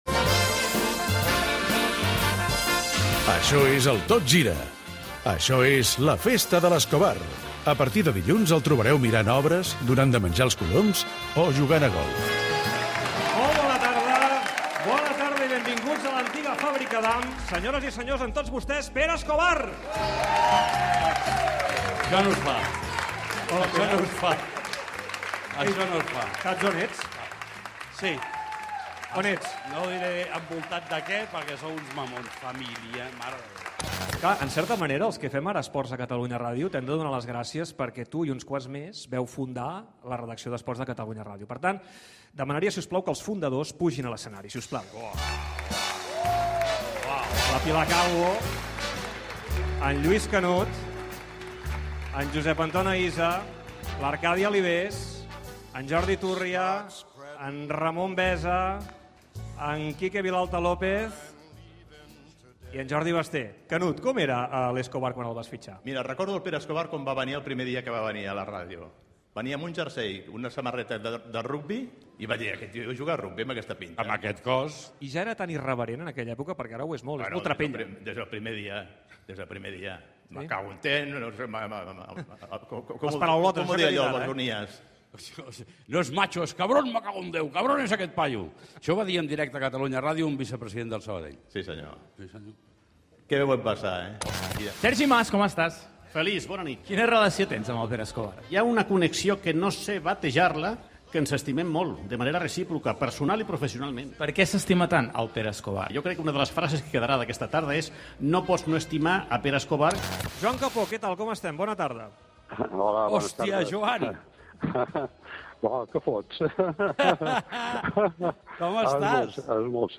Programa fet a l'antiga Fàbrica Damm
Esportiu